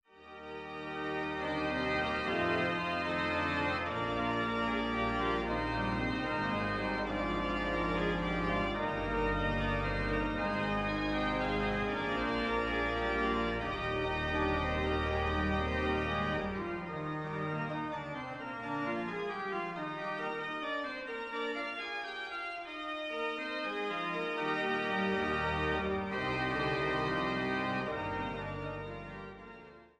an der größten historischen Orgel des Rheinlandes